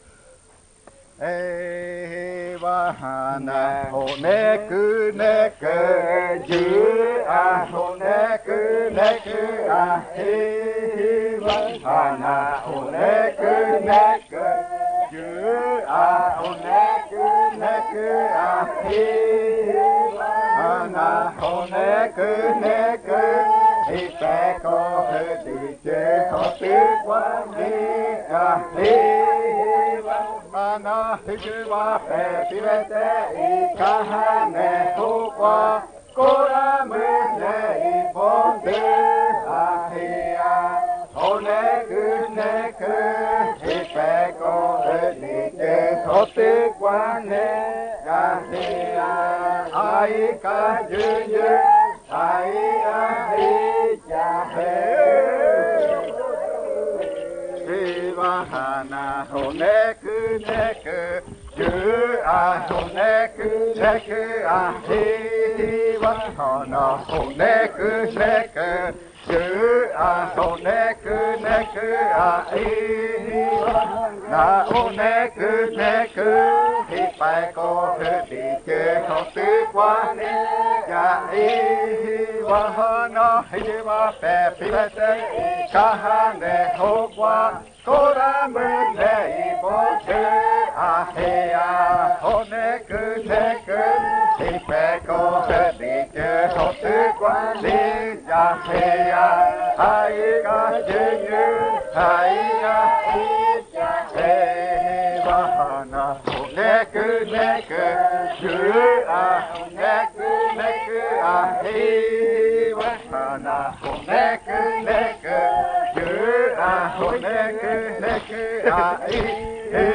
30. Baile de nombramiento. Canto n°18
Puerto Remanso del Tigre, departamento de Amazonas, Colombia